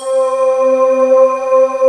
NOISE VOX.wav